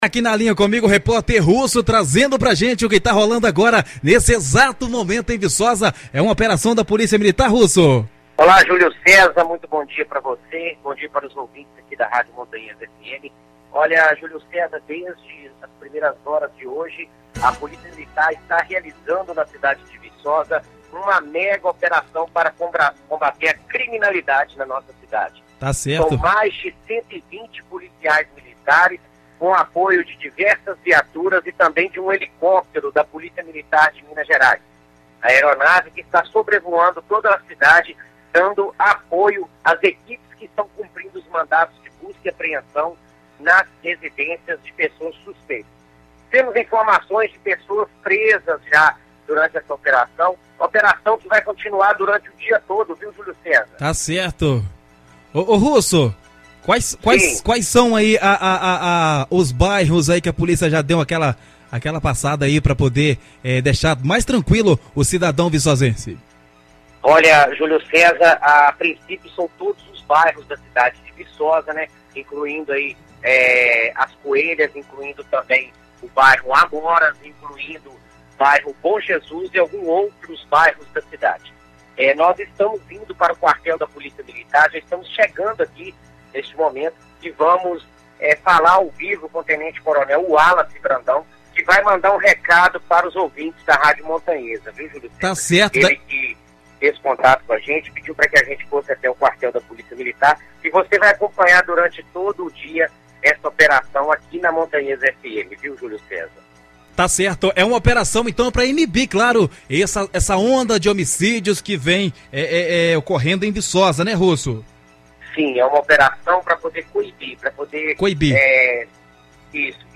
A reportagem da Rádio Montanhesa 106,5 FM está dando cobertura à operação e você confere as primeiras informações na matéria abaixo